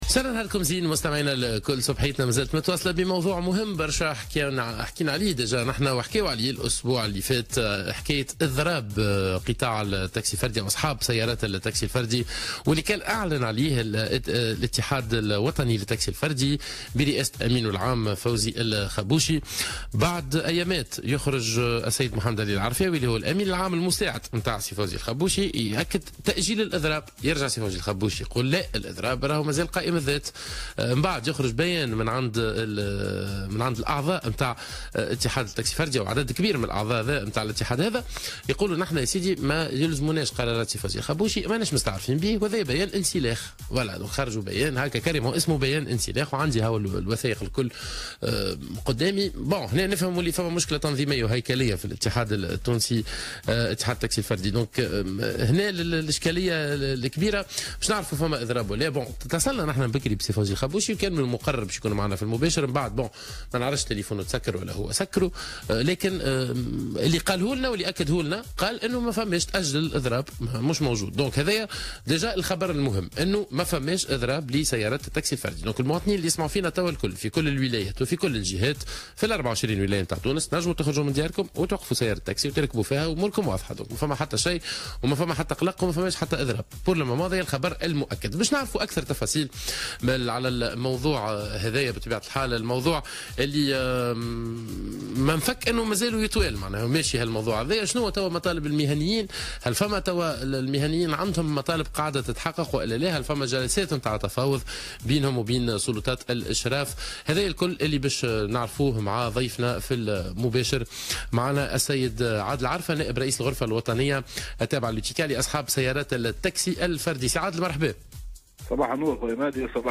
خلال مداخلته في برنامج 'صباح الورد'